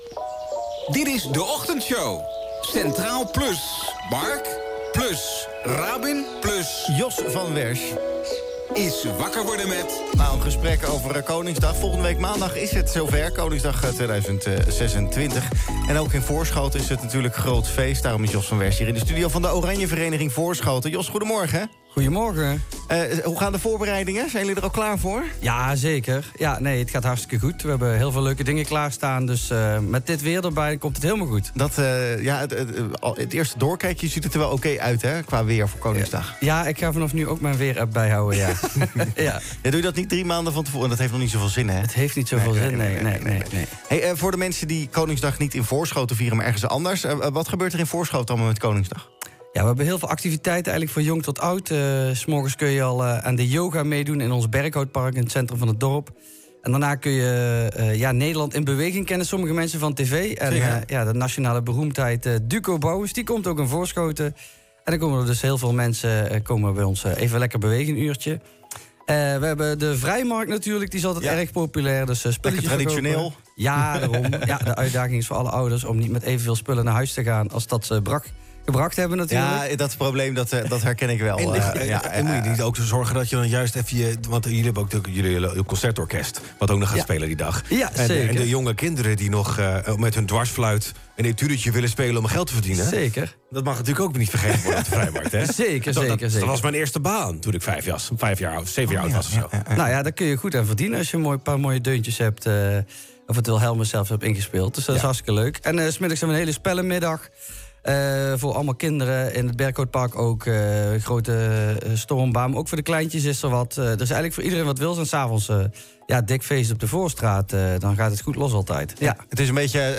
Tijdens de Centraal+ Ochtendshow geeft hij een inkijkje in de voorbereidingen, die volgens hem voorspoedig verlopen.